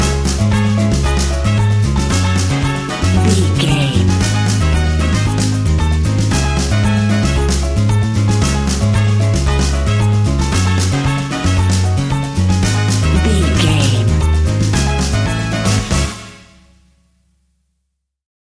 An exotic and colorful piece of Espanic and Latin music.
Aeolian/Minor
C#
maracas
percussion spanish guitar